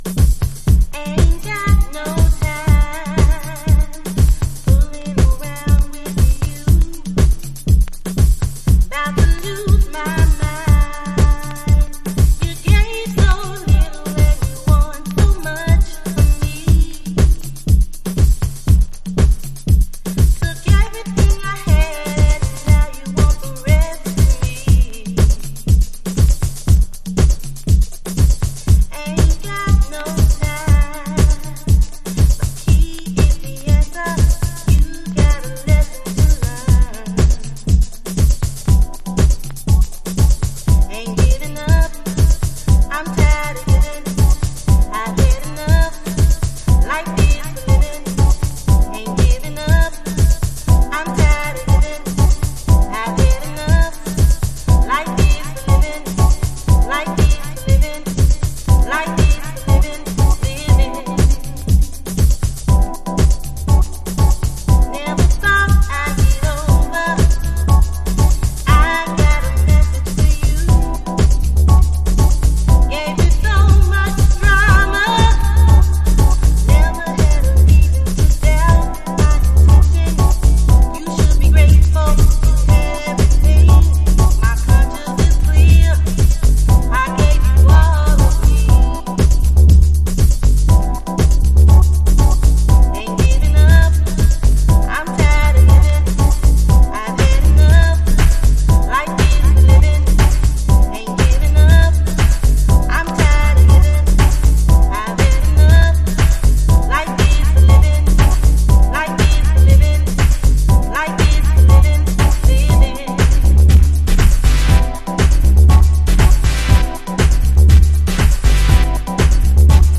Chicago Oldschool / CDH
この時期の荒々しさと浮遊感が同居したサウンドはたまんないです。